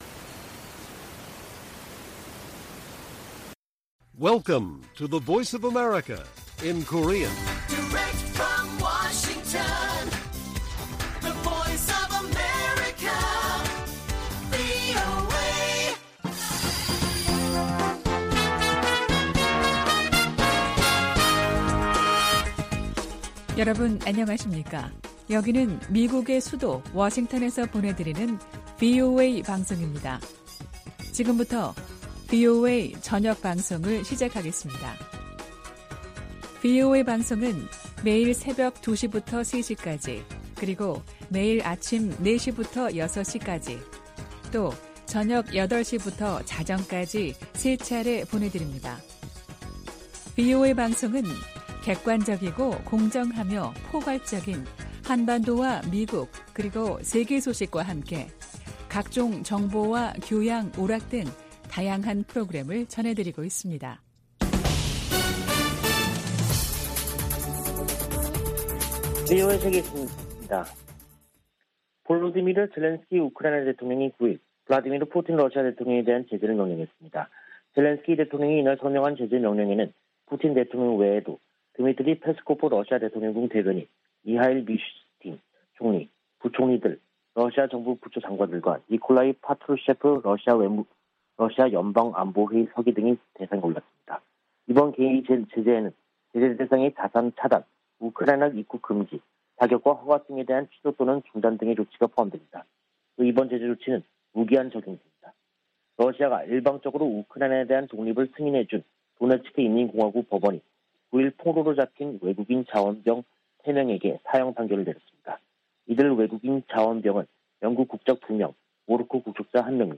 VOA 한국어 간판 뉴스 프로그램 '뉴스 투데이', 2022년 6월 10일 1부 방송입니다. 윤석열 한국 대통령이 한국 정상으로는 처음 나토 정상회의에 참석합니다. 북한이 핵실험을 강행하면 억지력 강화, 정보유입 확대 등 체감할수 있는 대응을 해야 한다고 전직 미국 관리들이 촉구했습니다.